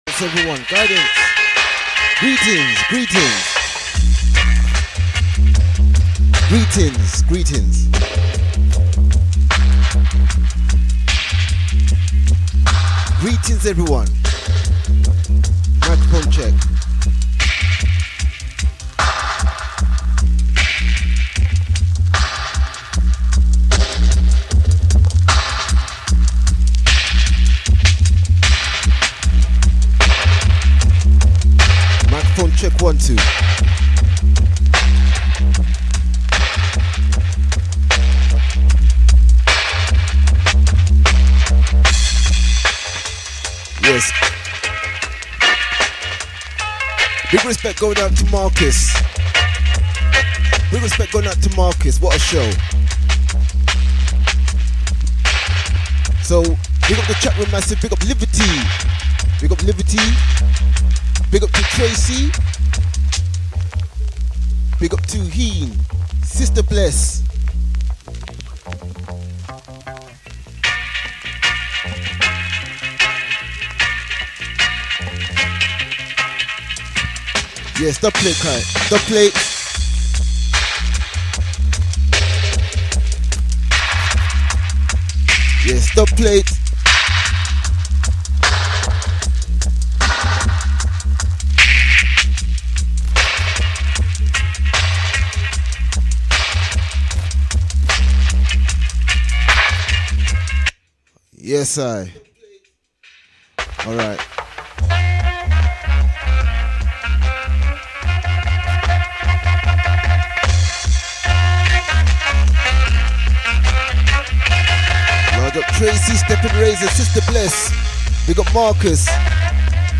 strictly 70s & 80s Roots music